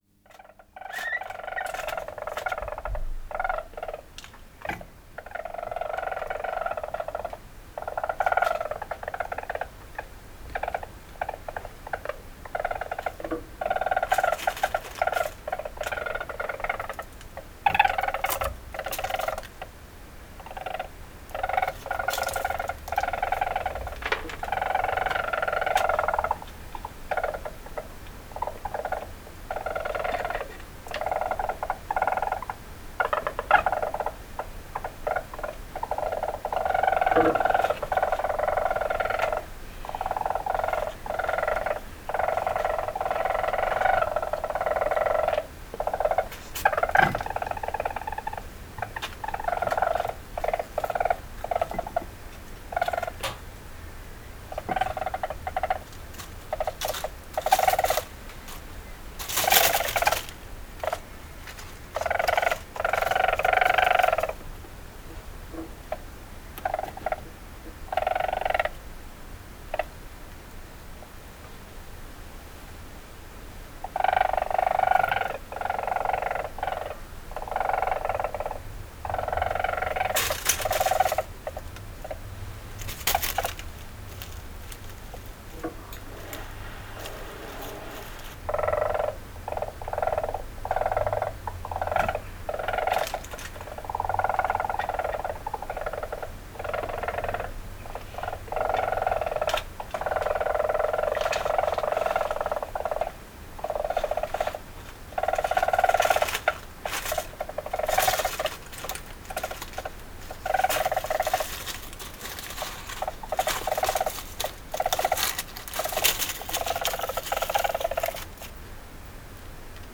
Mauswiesel-Geraeusche-Wildtiere-in-Europa.wav